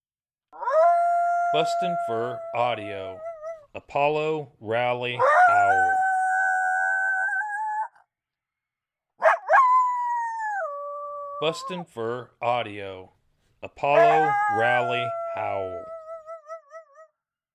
BFA’s Male coyote, Apollo, intensifying this lone howling series to instigate a vocal response from the wild coyotes.